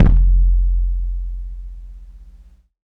Howl 808.wav